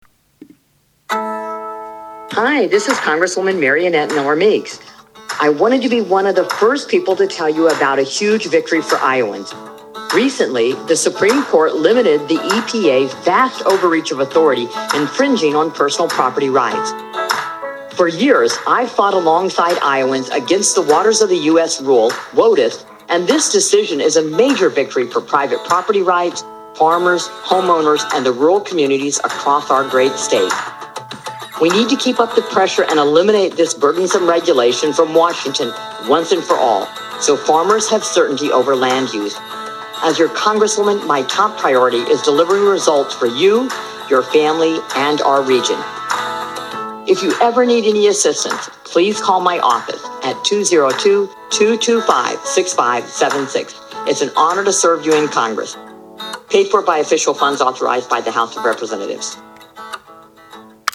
She narrated the 60-second commercial, which hailed a U.S. Supreme Court decision about an Environmental Protection Agency rule as a “huge victory for Iowans.”